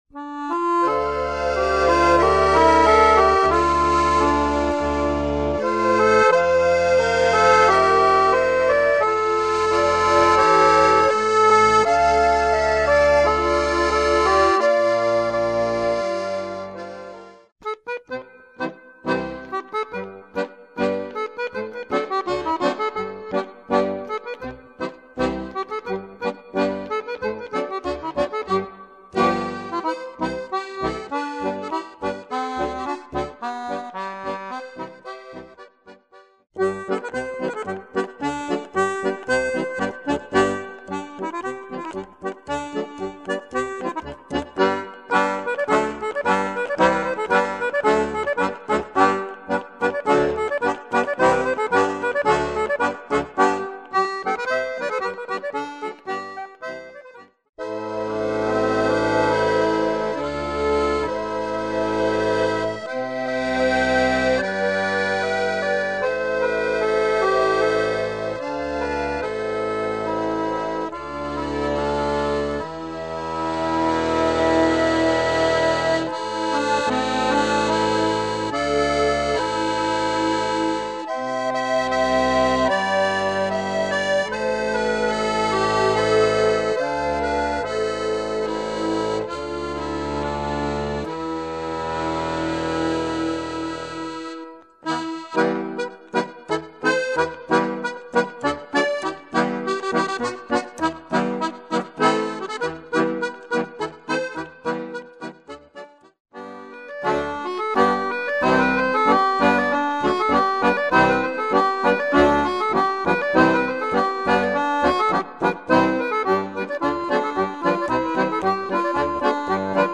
Seven duets for accordion upon russian folksongs